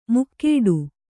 ♪ mukkēḍu